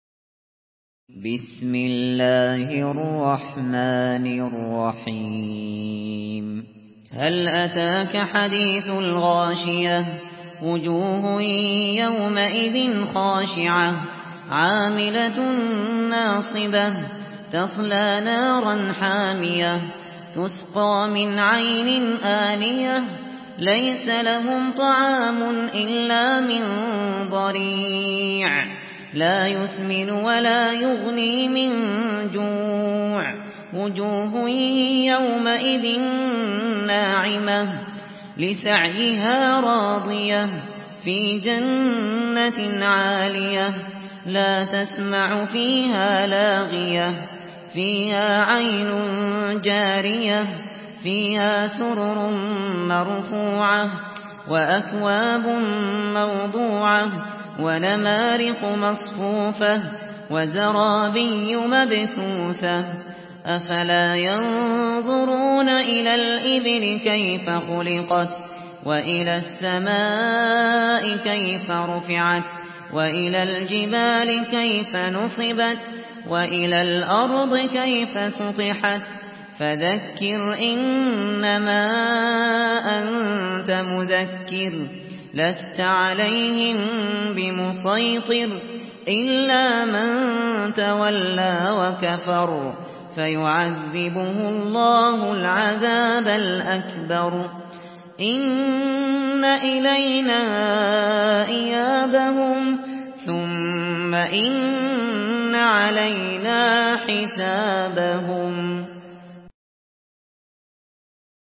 سورة الغاشية MP3 بصوت أبو بكر الشاطري برواية حفص عن عاصم، استمع وحمّل التلاوة كاملة بصيغة MP3 عبر روابط مباشرة وسريعة على الجوال، مع إمكانية التحميل بجودات متعددة.
مرتل